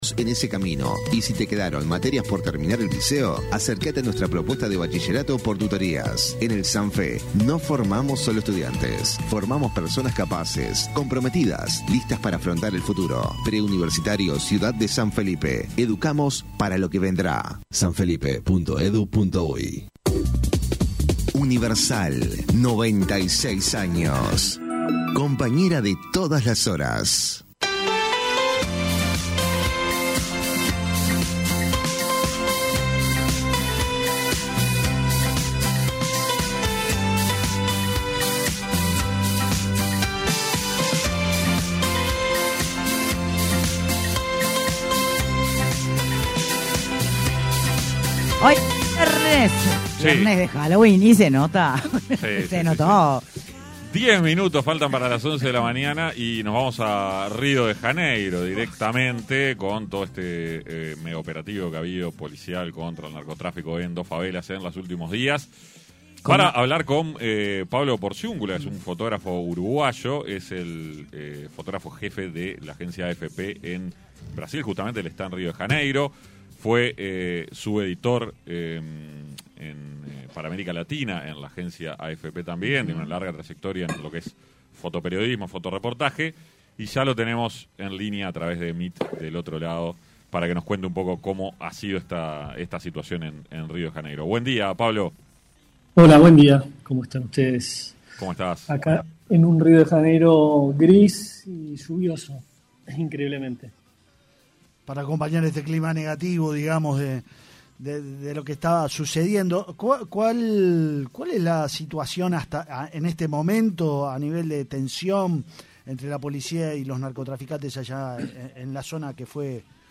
AUDIO En entrevista con Punto de Encuentro